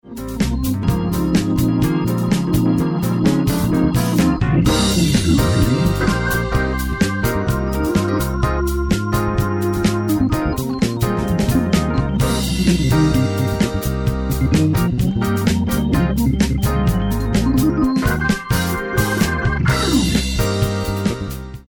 Hammond B3 organ
instrumental